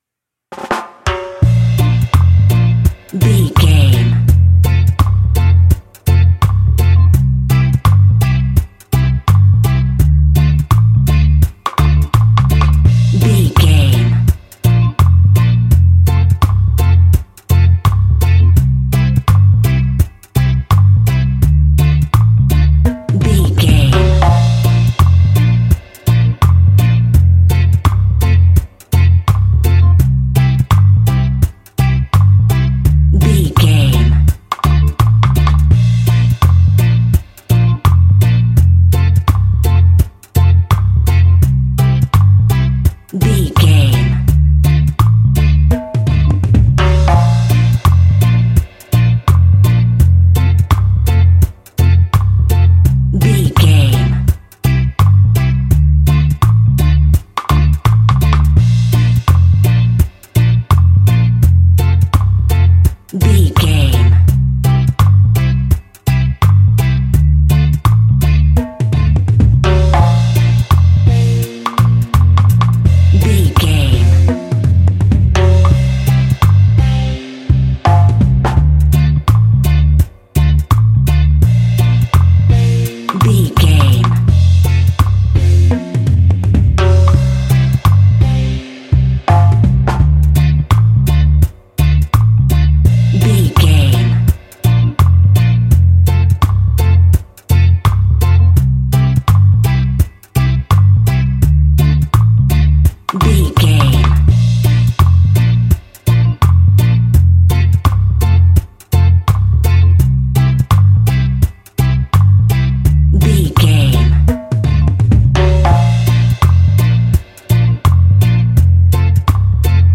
Classic reggae music with that skank bounce reggae feeling.
Aeolian/Minor
instrumentals
laid back
chilled
off beat
drums
skank guitar
hammond organ
percussion
horns